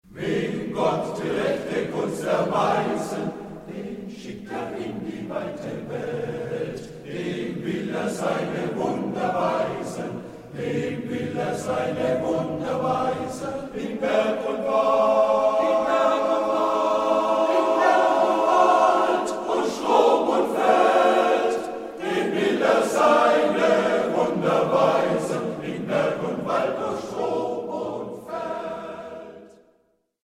Naturlieder